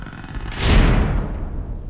Slamming doors
DOORCLS1.WAV